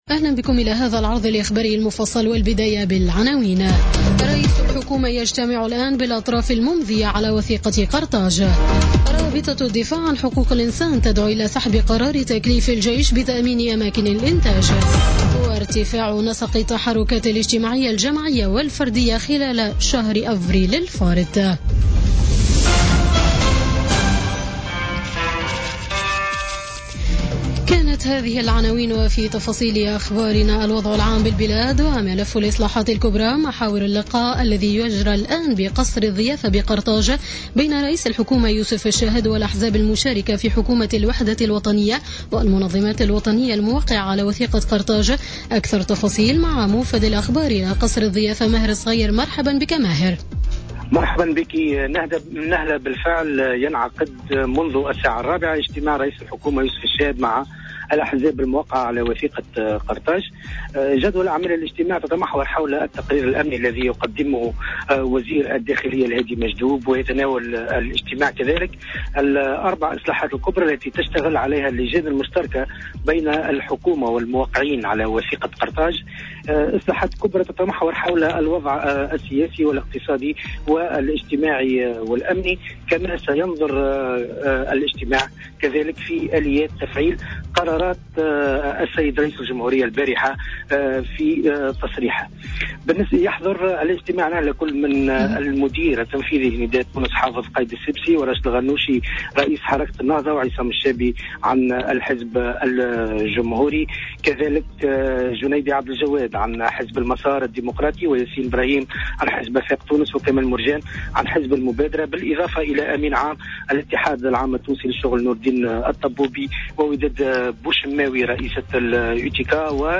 نشرة أخبار السابعة مساء ليوم الخميس 11 أفريل 2017